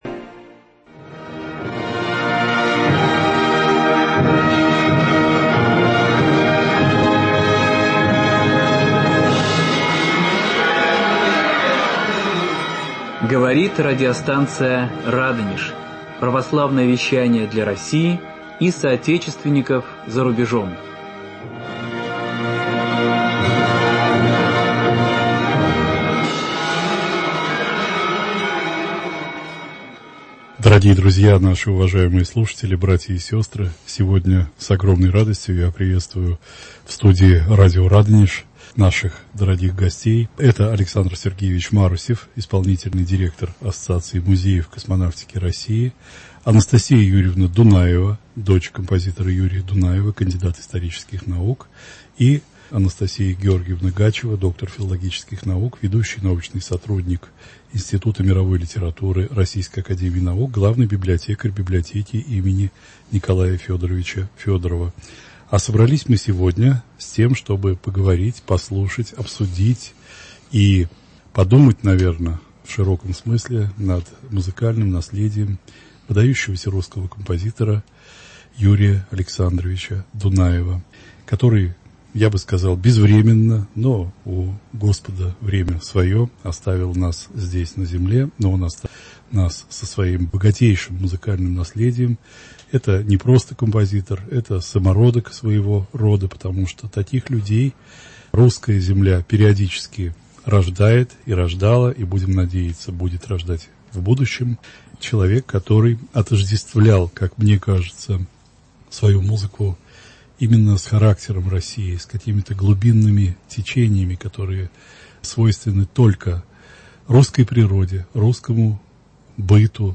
кандидат исторических наук собрались со мной в студии, чтобы поговорить (и послушать произведения) этого удивительного и самобытного композитора!